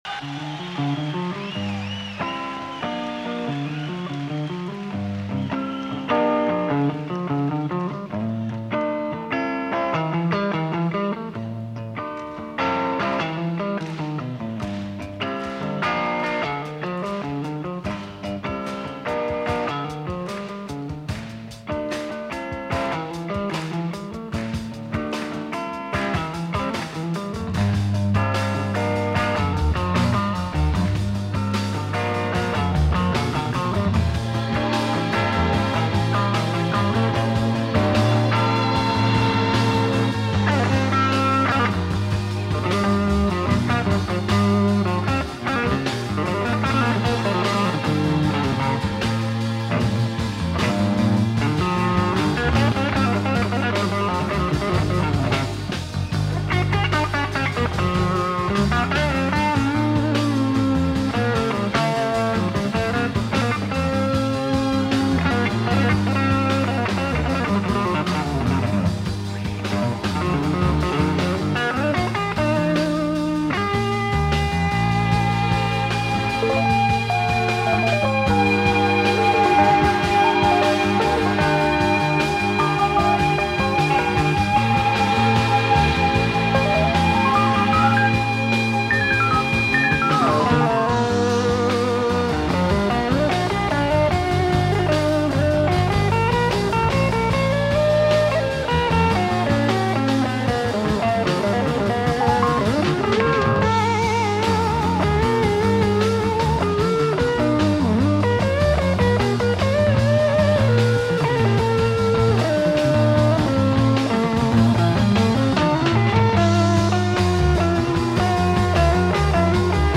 live album